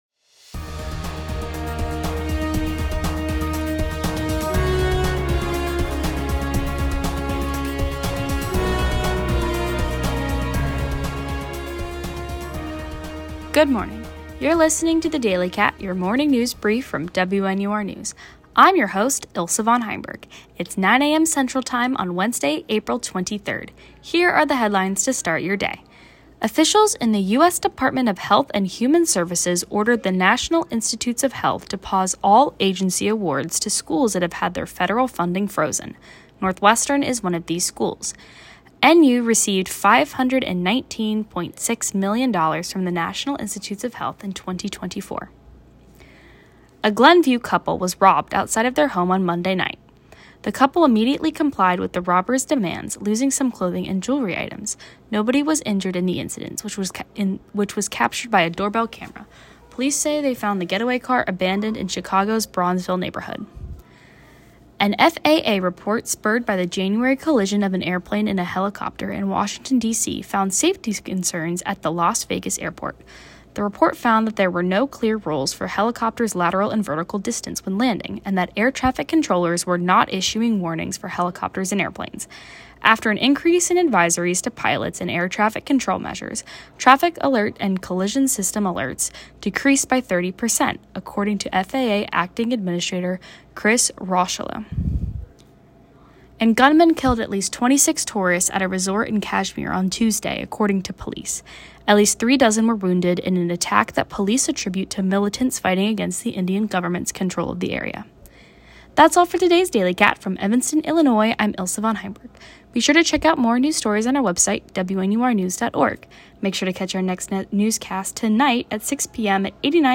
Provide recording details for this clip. DATE: April 23, 2025 NIH funding, Glenview robbery, FAA, Las Vegas airport, Kashmir resort shooting. WNUR News broadcasts live at 6 pm CST on Mondays, Wednesdays, and Fridays on WNUR 89.3 FM.